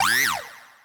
snd_noelle_scared.wav